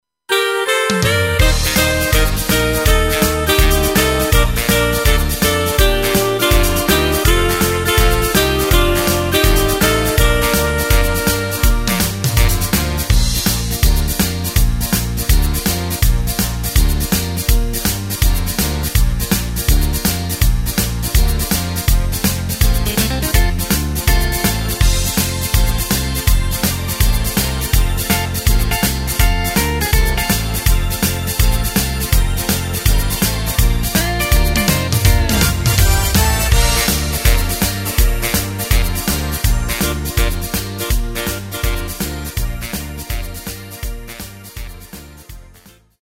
Takt:          4/4
Tempo:         164.00
Tonart:            Bb
Boogie Woogie aus dem Jahr 2024!
Playback mp3 Mit Drums